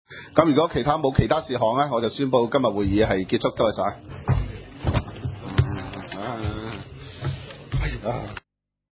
委员会会议的录音记录
地点: 元朗桥乐坊2号元朗政府合署十三楼会议厅